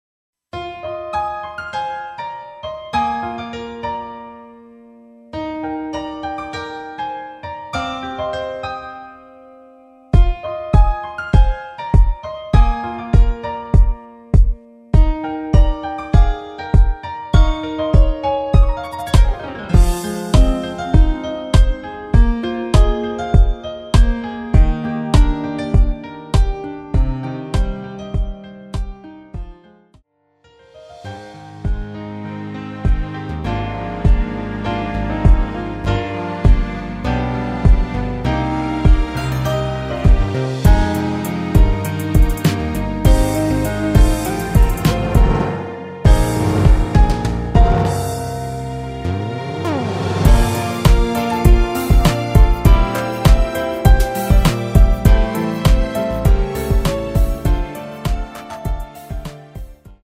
내린 MR입니다.
Eb
◈ 곡명 옆 (-1)은 반음 내림, (+1)은 반음 올림 입니다.
앞부분30초, 뒷부분30초씩 편집해서 올려 드리고 있습니다.
중간에 음이 끈어지고 다시 나오는 이유는